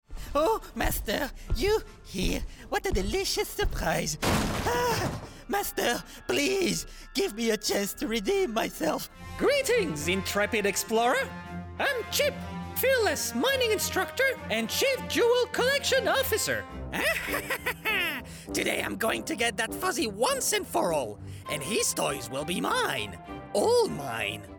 中低音域の声で、暖かさと親しみやすさを兼ね備えています。
コミカルな(英語)